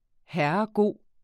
Udtale [ ˈhæɐ̯ʌˈgoˀ ]